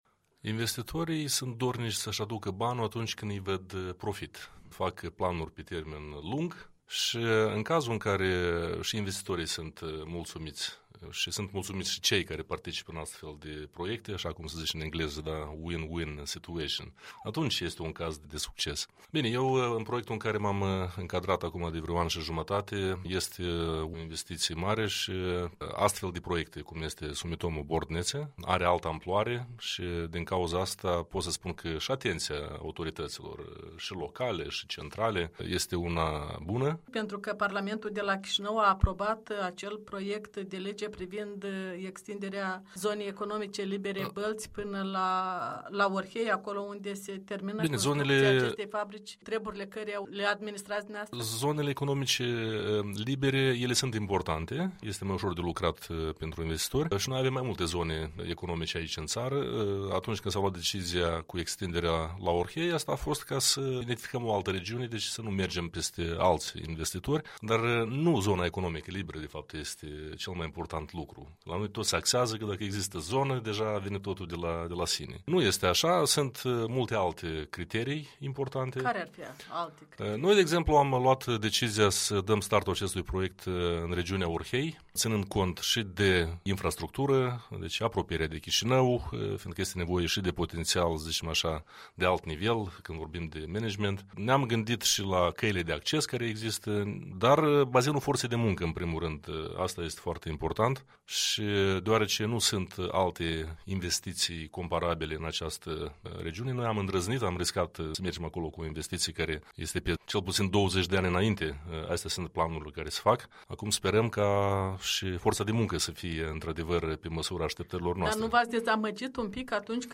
Un interviu cu directorul reprezentanței din R. Moldova a companiei nipono-germane japonezo-germană.